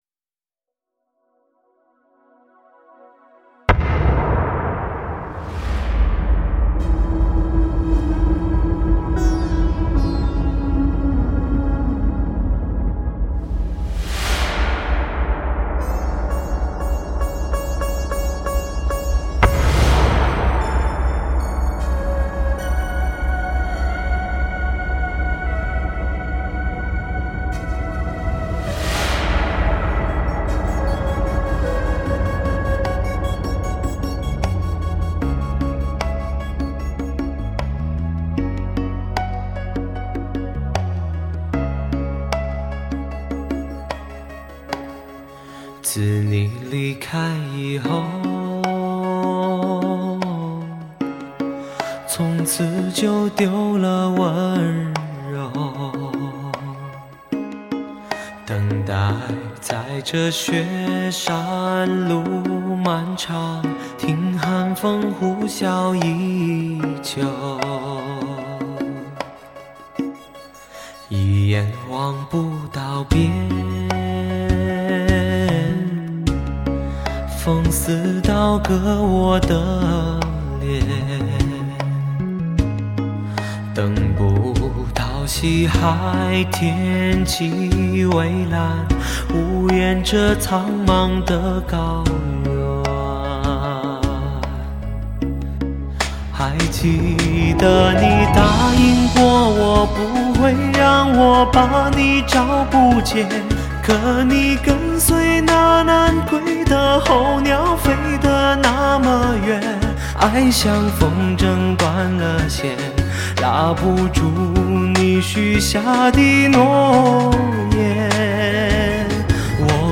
中文乐坛最值得珍藏的完美男声，精致丰满的演绎，风入骨的音色，清新携永的内涵。